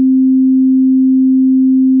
c4.wav